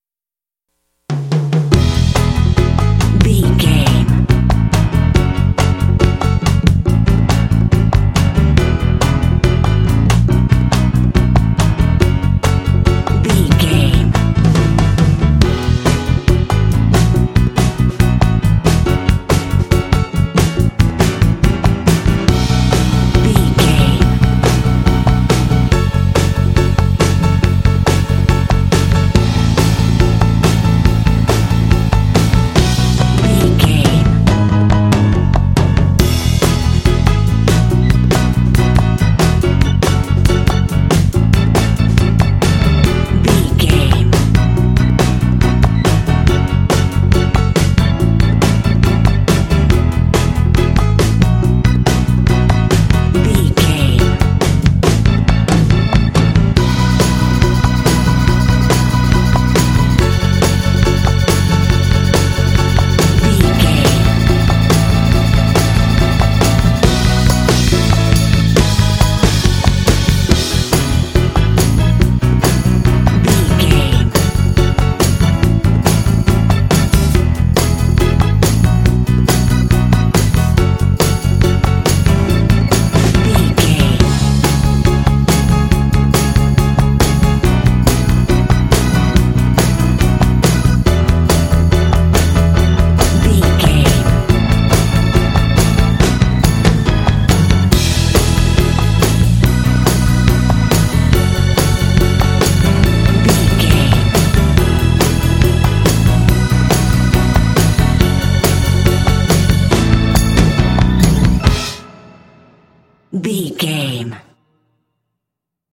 Great for lively kids games.
Ionian/Major
lively
playful
cheerful/happy
optimistic
drums
bass guitar
piano
electric organ
percussion
country rock